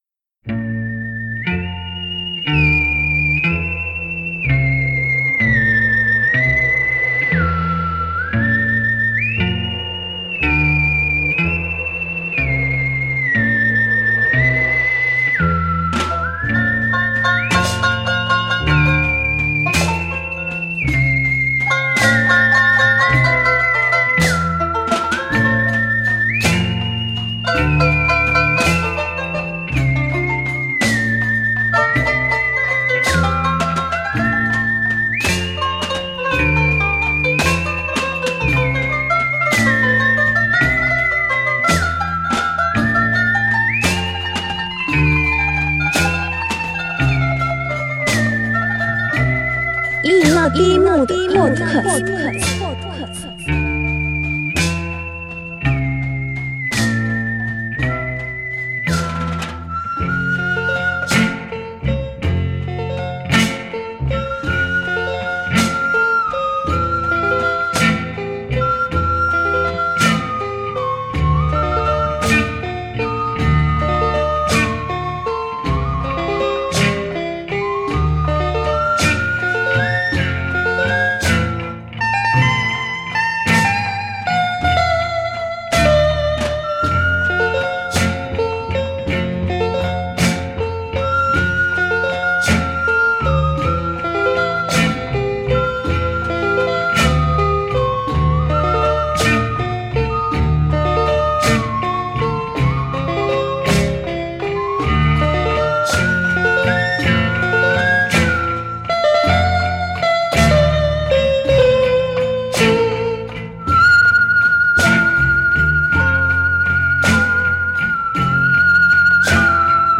A deep and nostalgic playlist